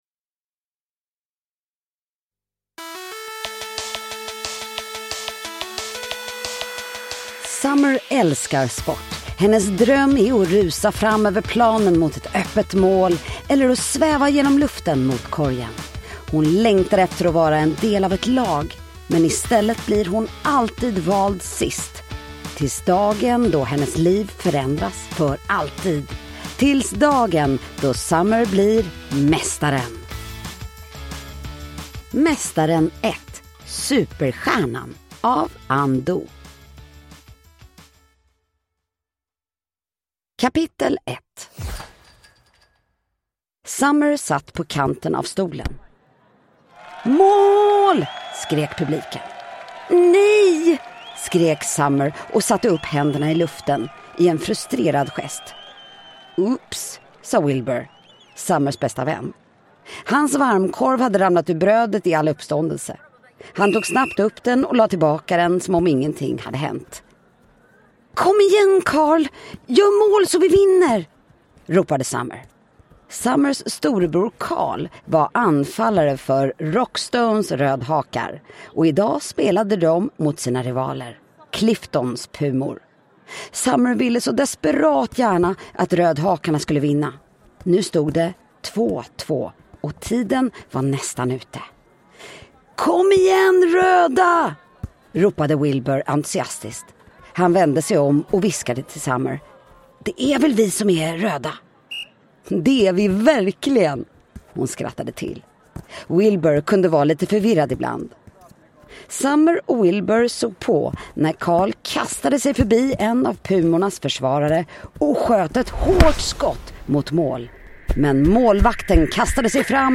Superstjärnan – Ljudbok
Högt tempo med knasig och charmig humor.